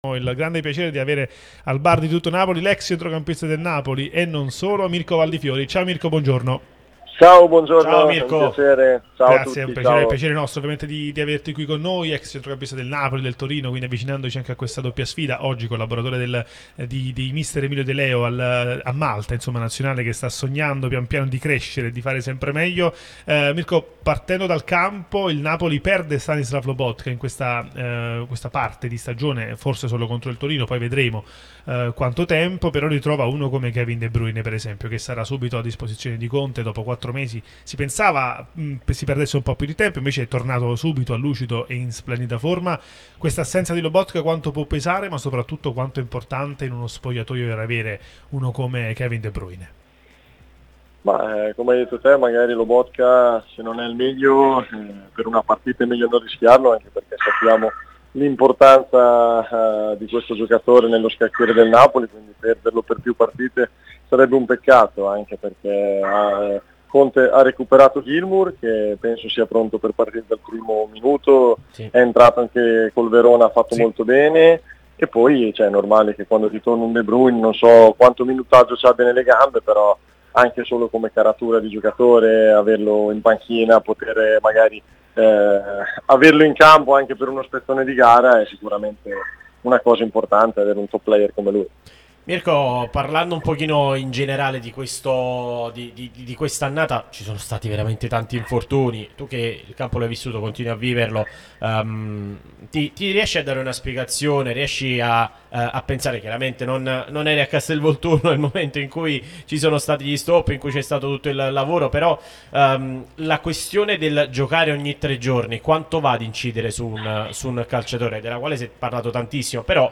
Mirko Valdifiori, ex centrocampista del Napoli, è intervenuto su Radio Tutto Napoli, prima radio tematica sul Napoli, che puoi seguire sulle app gratuite (scarica qui per Iphone o per Android), qui sul sito anche in video